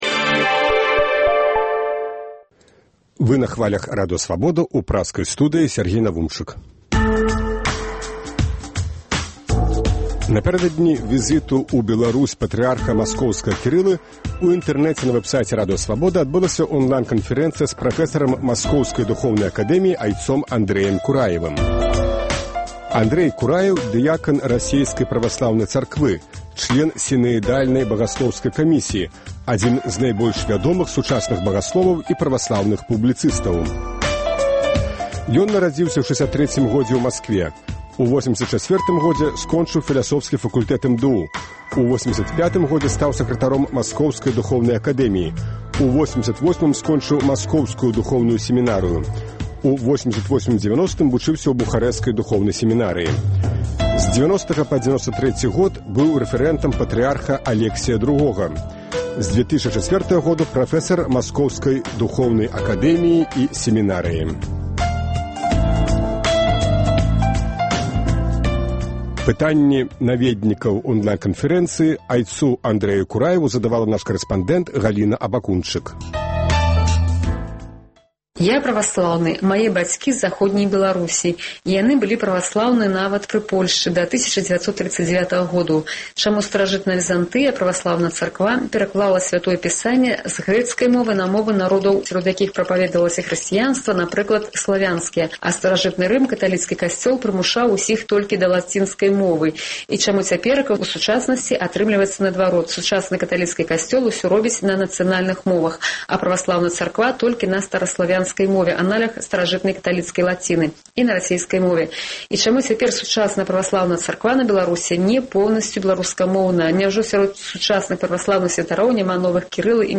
Онлайн канфэрэнцыя
На пытаньні наведнікаў вэб-сайту Свабоды адказвае прафэсар Маскоўскай духоўнай акадэміі айцец Андрэй Кураеў.